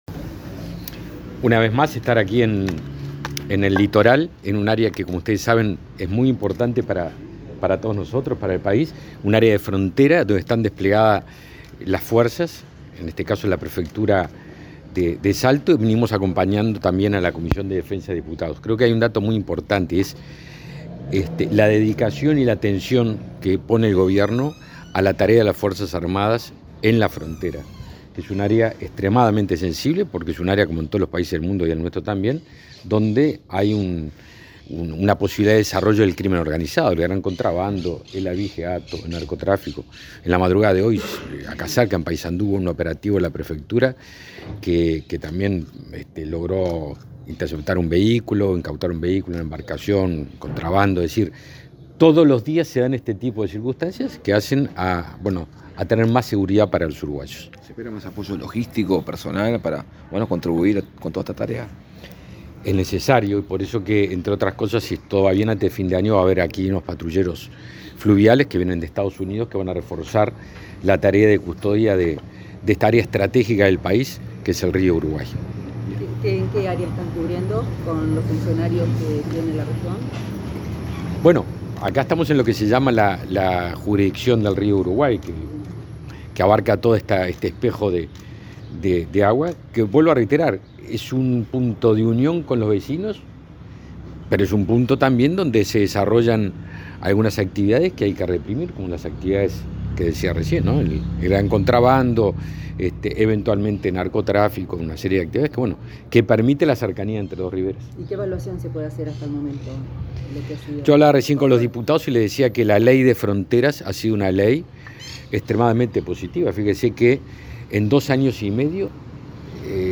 Declaraciones del ministro de Defensa Nacional
Declaraciones del ministro de Defensa Nacional 31/08/2022 Compartir Facebook X Copiar enlace WhatsApp LinkedIn El ministro de Defensa Nacional, Javier García, autoridades militares e integrantes de la Comisión de Defensa de la Cámara de Representantes observaron el despliegue conjunto de efectivos del Ejército, la Armada Nacional y la Fuerza Aérea en el departamento de Salto en cumplimiento de la ley de patrullaje fronterizo. Luego, García dialogó con la prensa.